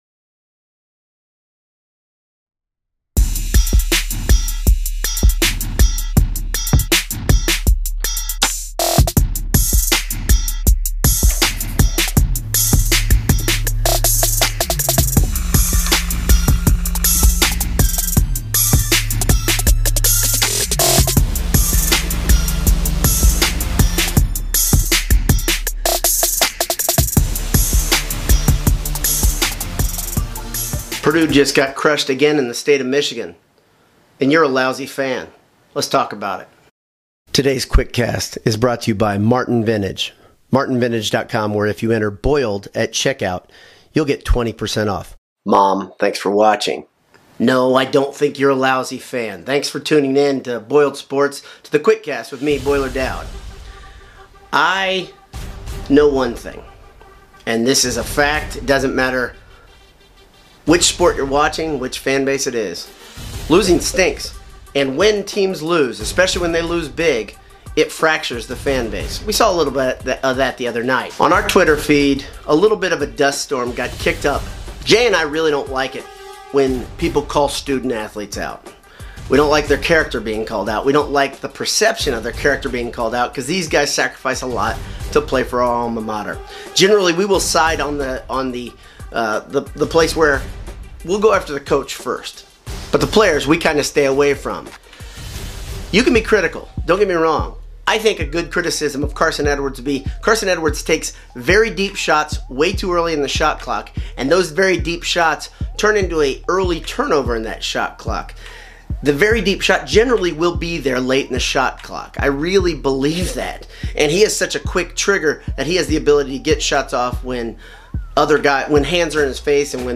Bad Losses Make Bad Fans (A QuickCast Discussion) — Boiled Sports